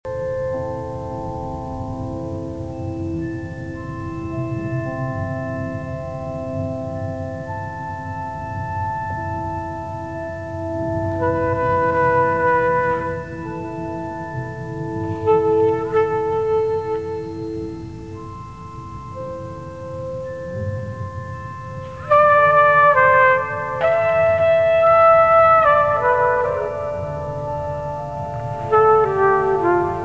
tromba elettrica e acustica, voci, loops, bass synth